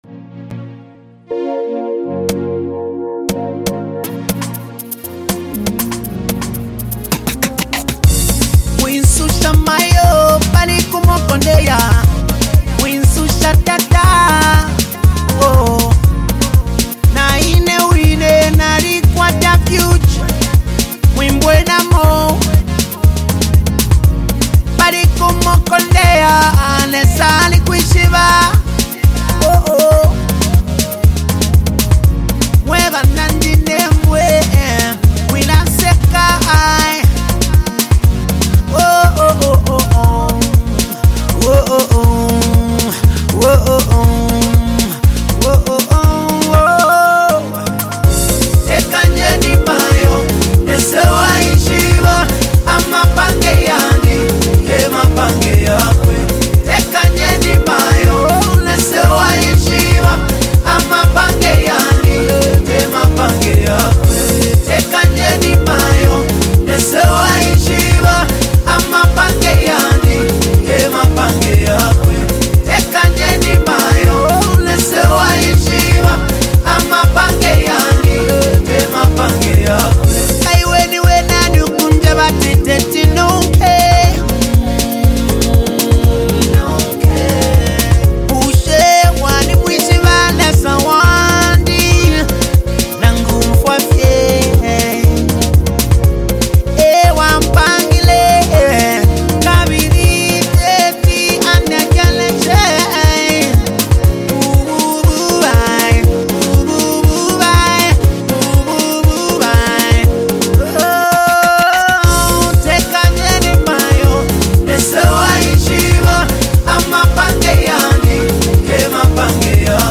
WORSHIP SONG
ZAMBIAN GOSPEL MUSIC